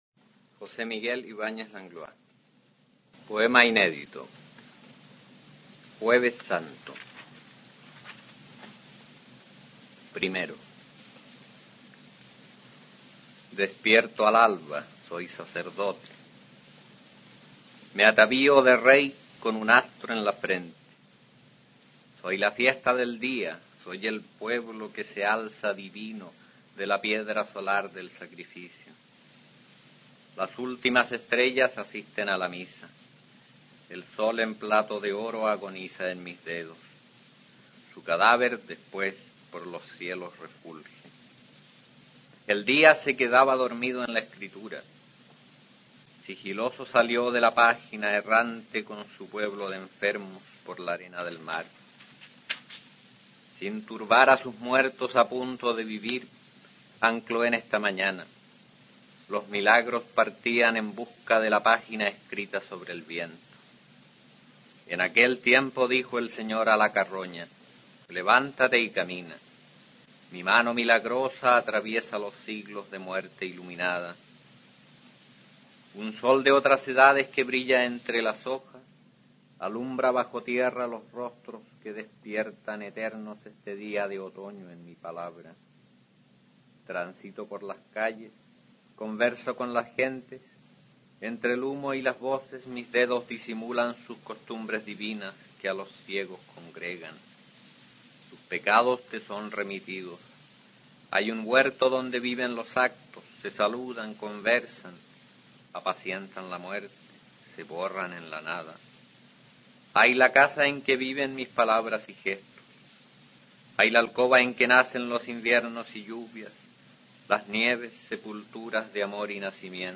Aquí se puede escuchar al autor chileno José Miguel Ibáñez Langlois recitando su poema Jueves Santo, del libro "Eterno es el día" (1968).